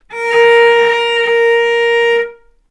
vc_sp-A#4-ff.AIF